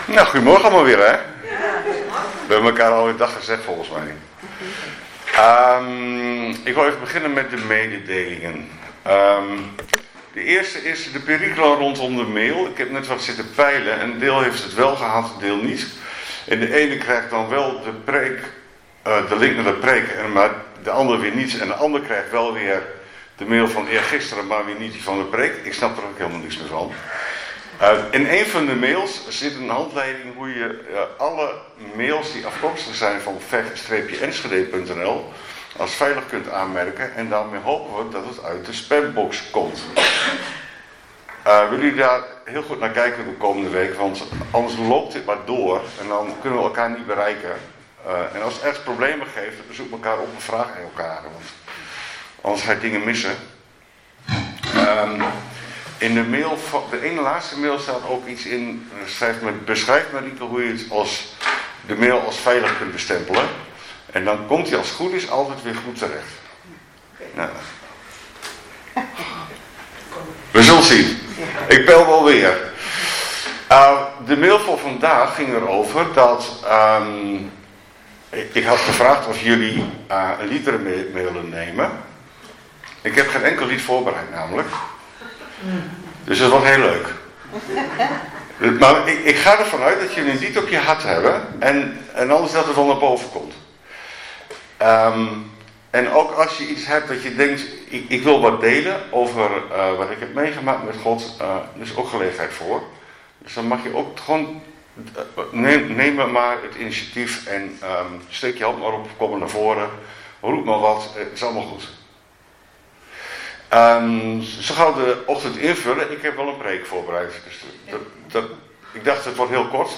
8 februari 2026 dienst - Volle Evangelie Gemeente Enschede